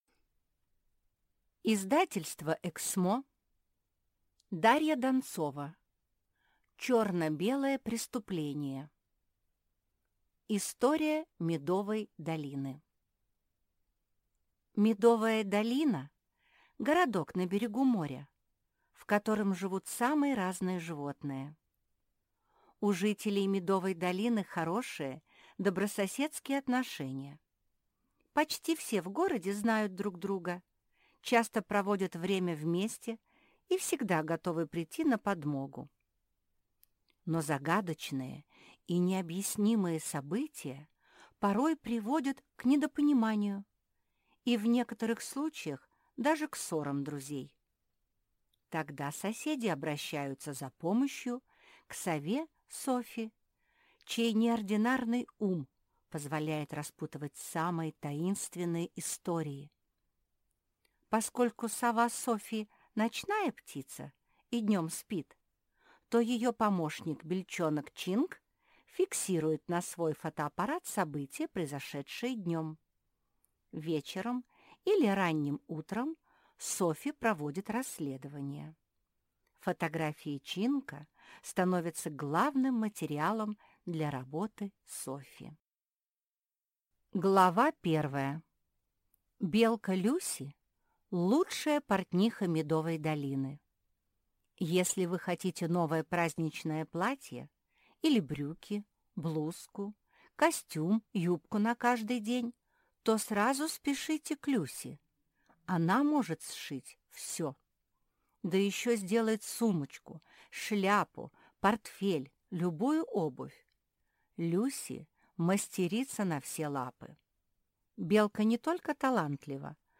Аудиокнига Черно-белое преступление | Библиотека аудиокниг
Прослушать и бесплатно скачать фрагмент аудиокниги